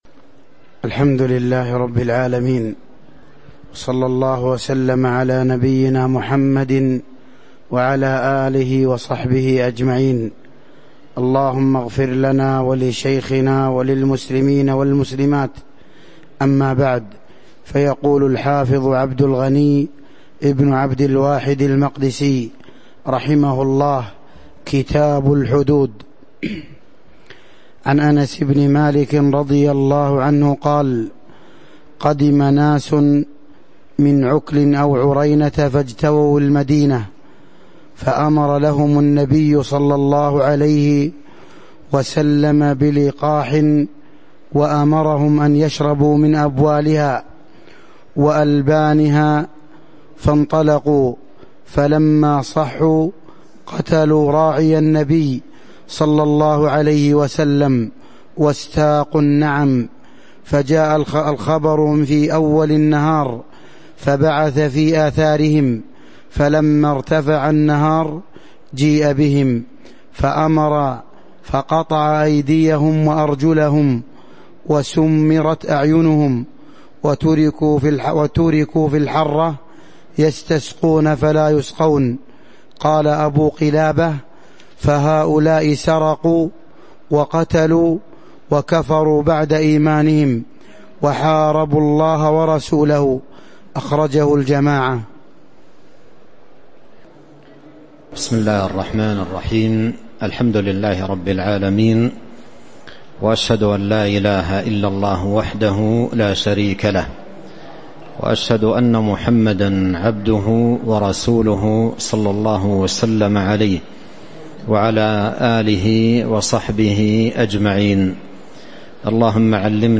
تاريخ النشر ٢٤ رجب ١٤٤٤ هـ المكان: المسجد النبوي الشيخ